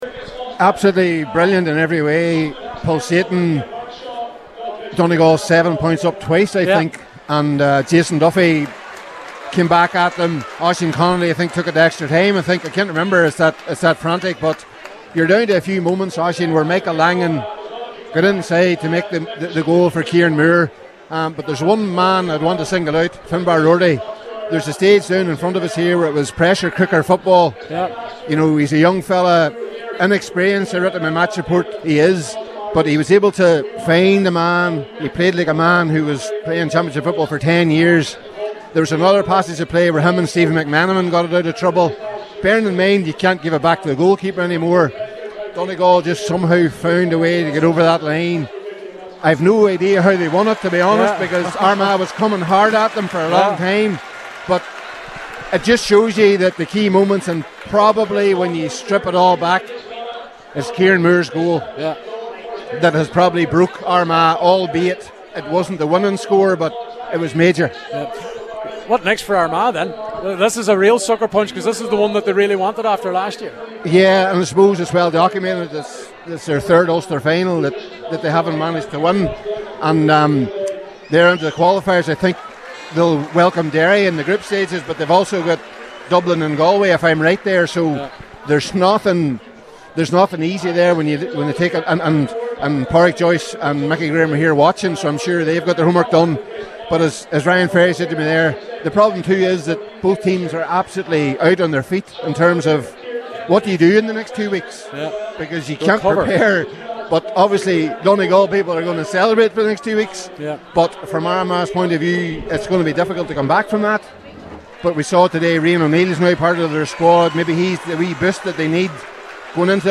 shortly after the final whistle